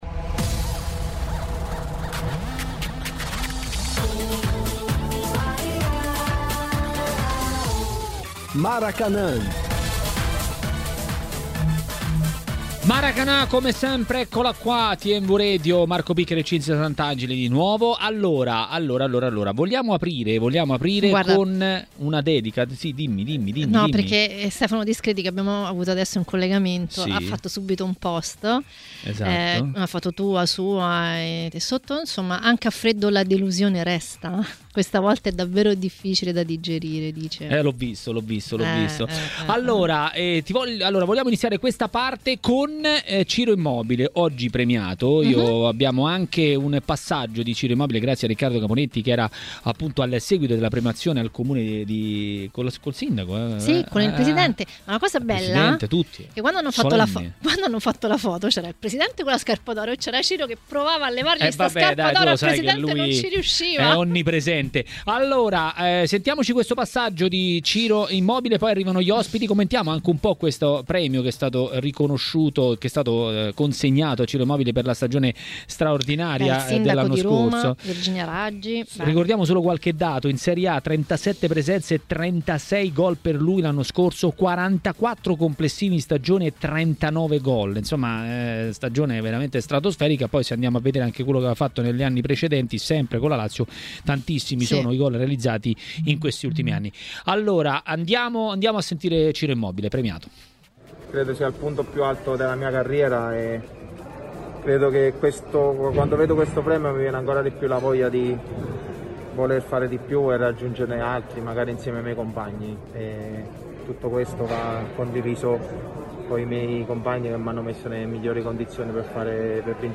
Per commentare le notizie di giornata a Maracanà, nel pomeriggio di TMW Radio, è intervenuto l'ex portiere Simone Braglia.